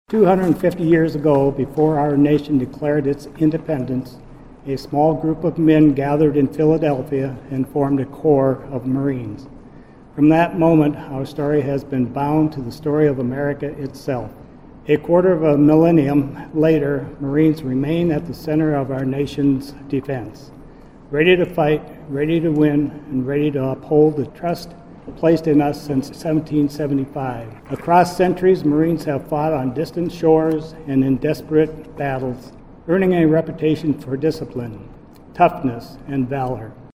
PIERRE, S.D.(DRGNews)-The United States Marine Corps turned 250 years old Monday and South Dakota marked the occasion with a ceremony and official cake cutting in Pierre.